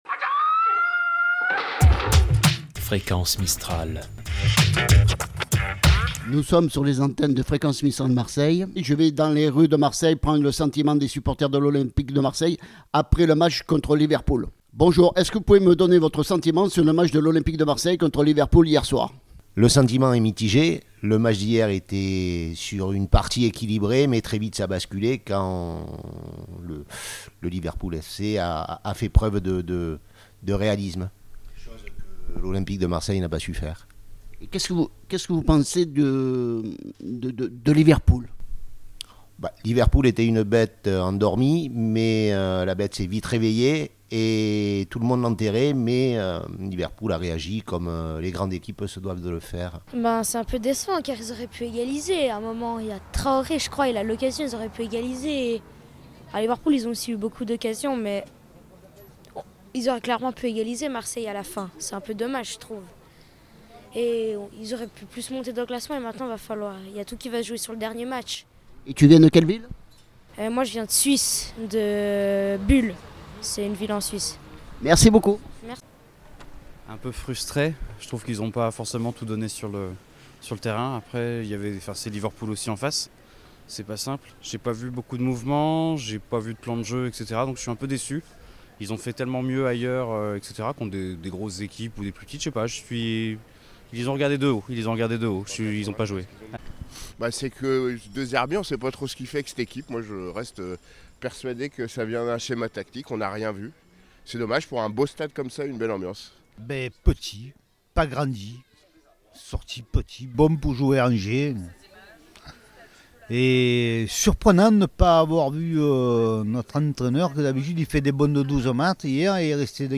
micro-trottoir
parcourt les rues de Marseille pour vous donner la parole sur les sujets de son choix. Cette semaine, il recueille votre avis sur le match OM – Liverpool !